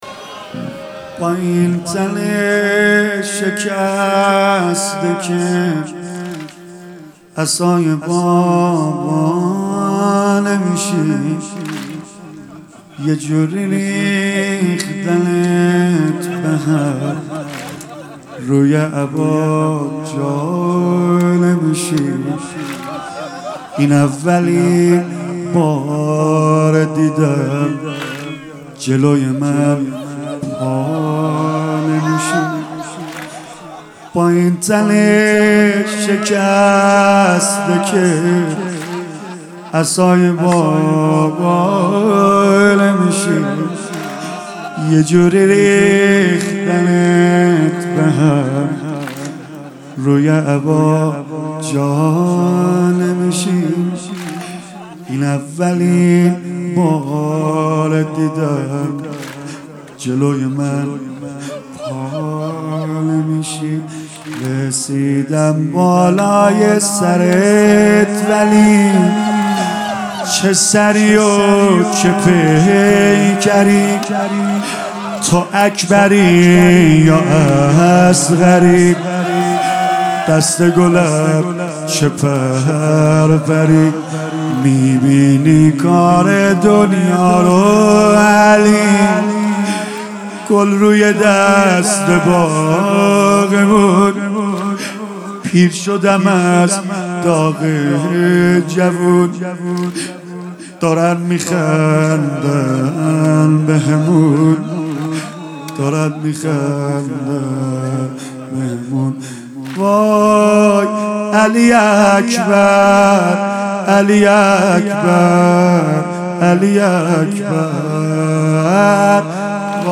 خیمه گاه - جبهه فرهنگی شهید ابراهیم هادی - واحد | با این تنِ شکسته که عصای بابا نمیشی | سید رضا نریمانی